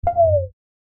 Sample Sound Effects
whoosh.mp3